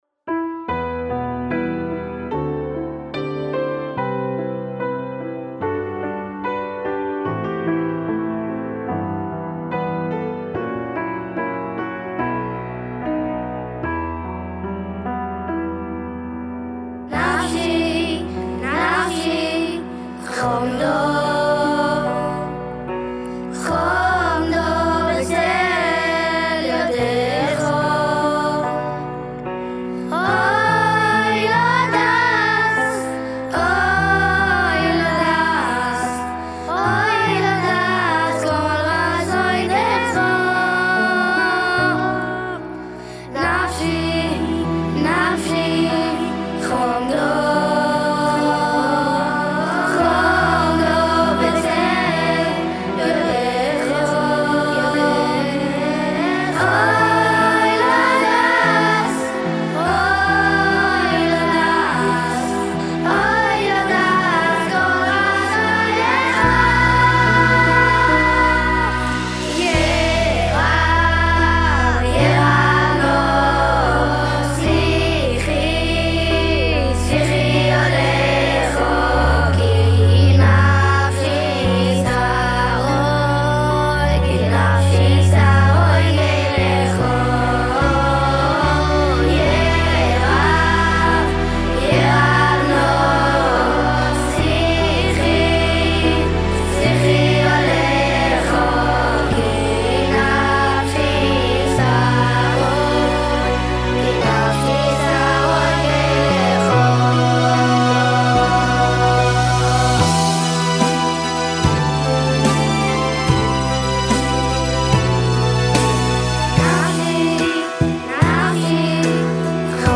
אני יעלה לכם שיר שעשיתי עם מקהלת ילדים שהייתה לי בחורף הקלטתי אותם באופן מאוד פשוט כל אחד לבד בגלל שהיה לי רק זוג אוזניות.
קבצים מצורפים נפשי - מקהלה.mp3 10.2 MB · צפיות: 78